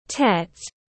Ngày Tết tiếng anh gọi là Tet, phiên âm tiếng anh đọc là /tet/